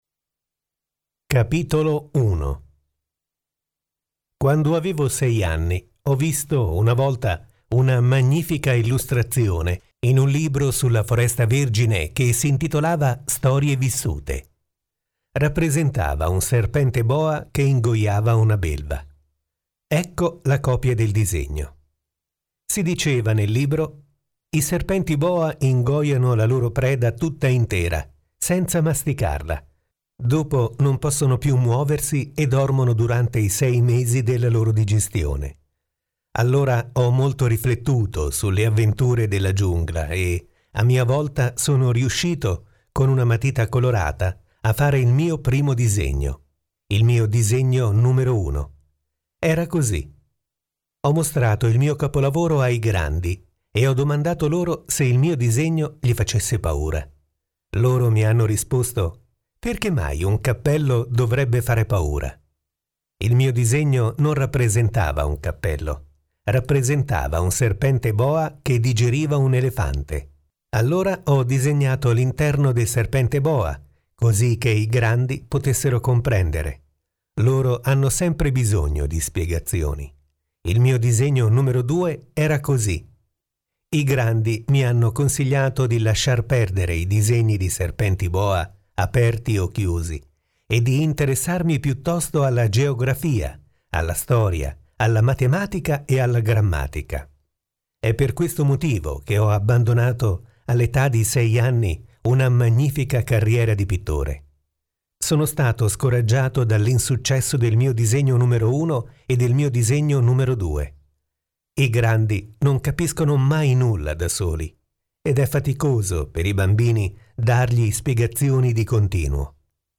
Capitolo 1 audiolibro: